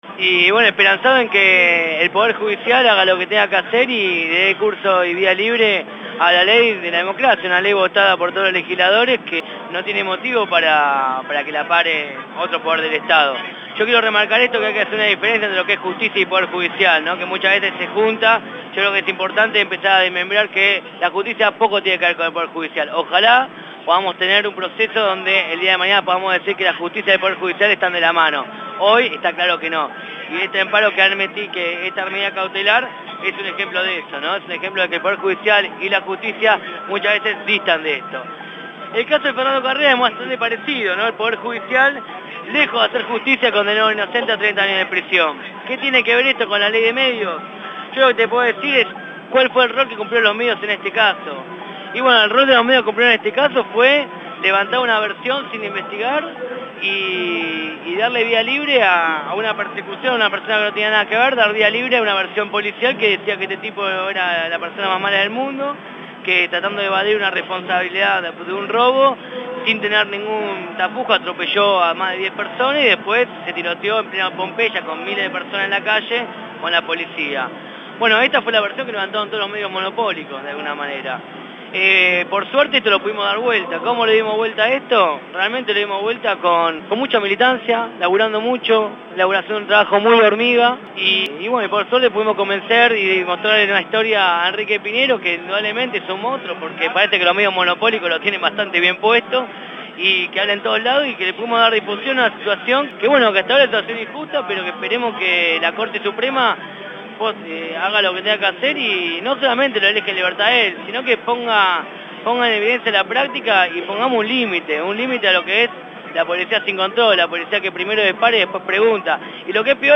En ésta radio abierta se pudieron escuchar las voces de…